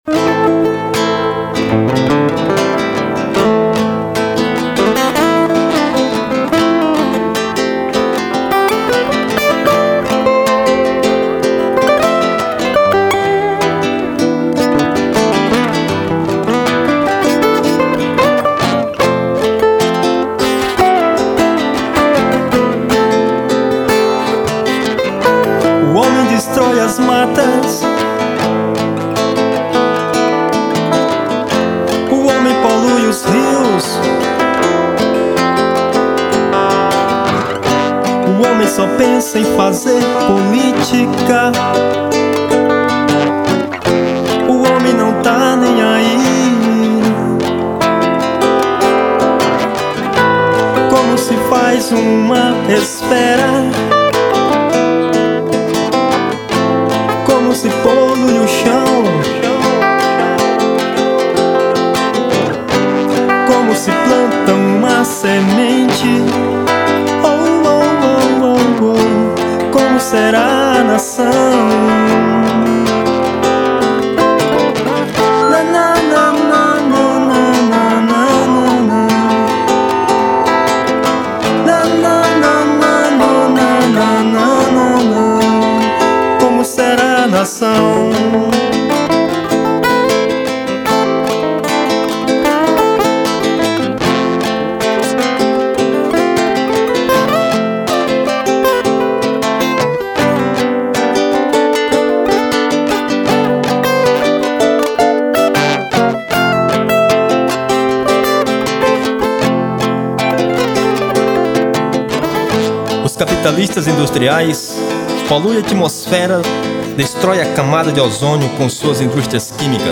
xote